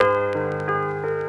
Keys_11.wav